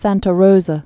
(săntə rōzə)